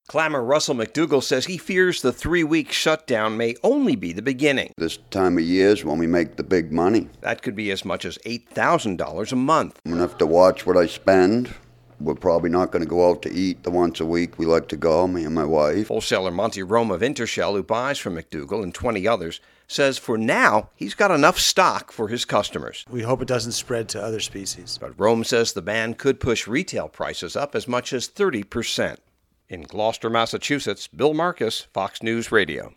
HAS MORE FROM GLOUCESTER, MASS.